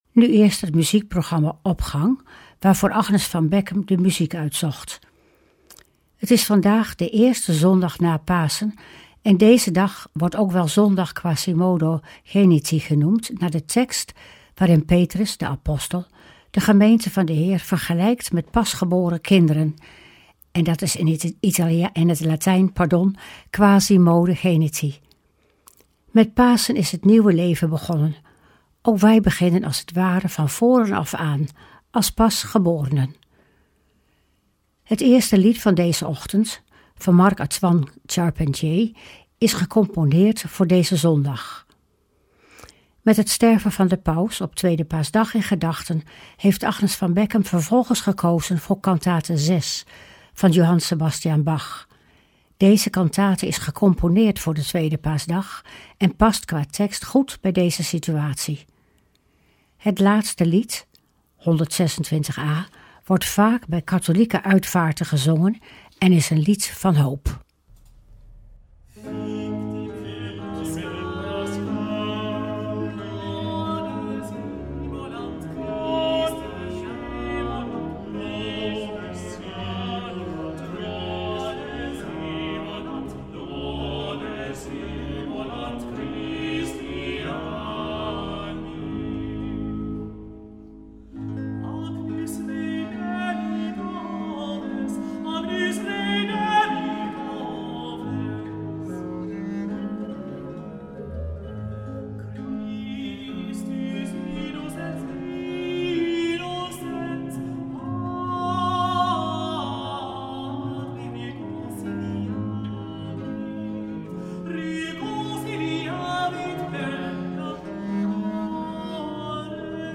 Opening van deze zondag met muziek, rechtstreeks vanuit onze studio.